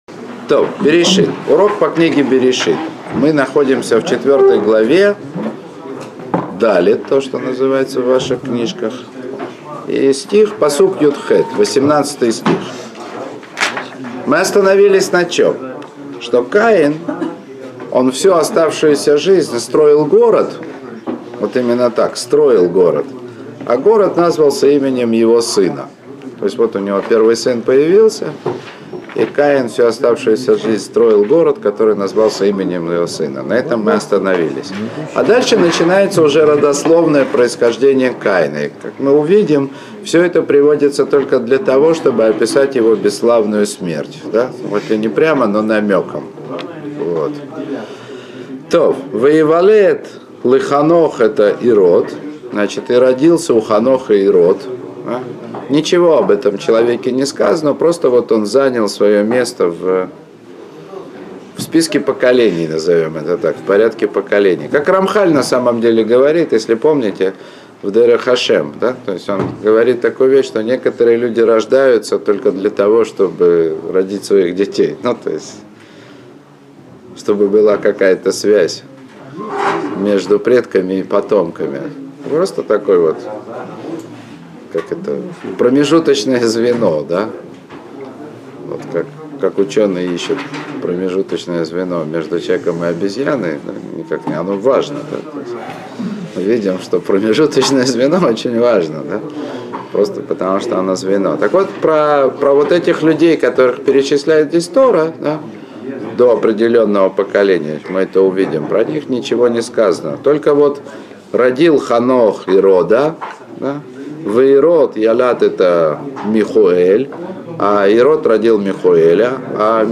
Уроки по книге Берейшит.